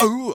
ow.wav